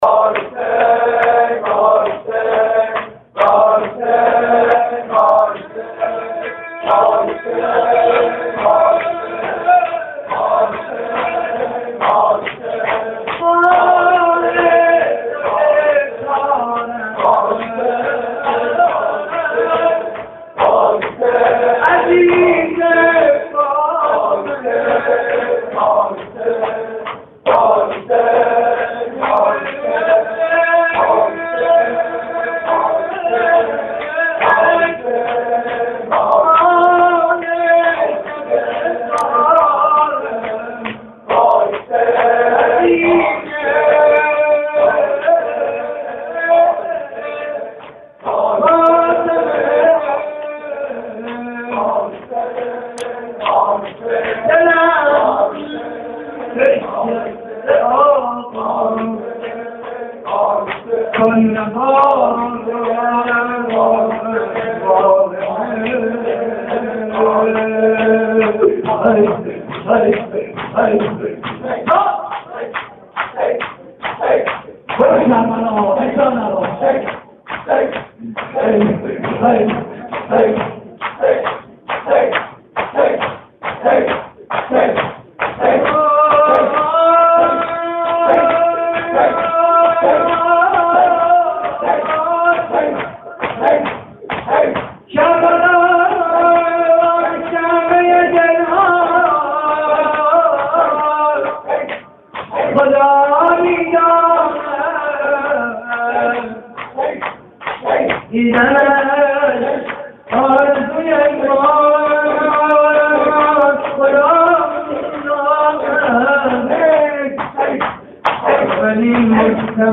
مراسم هفتگی (پیشواز محرم) / هیئت کانون دانش آموزی حضرت سیدالکریم (ع)؛ شهرری - 11 آذر 89
صوت مراسم:
شور و نوا: کربلا کعبه‌ی دل‌هاست؛ پخش آنلاین |